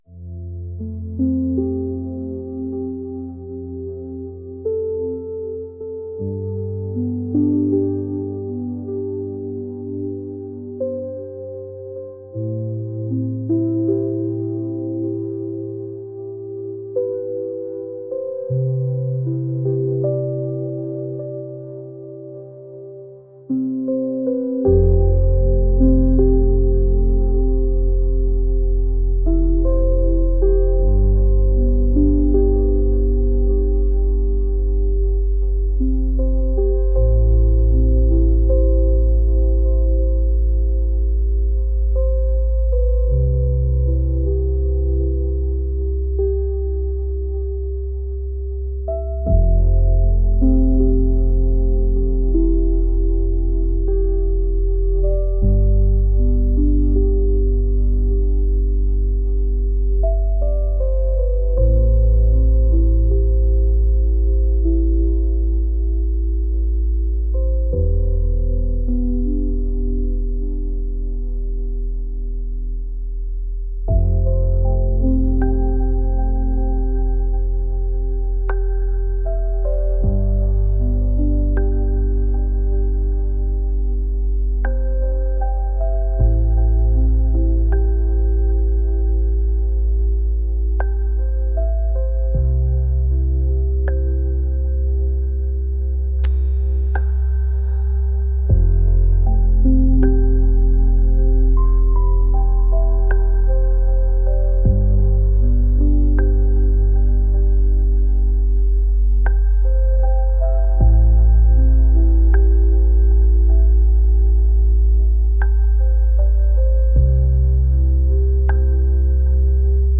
ambient